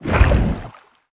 Sfx_creature_spikeytrap_pulling_05.ogg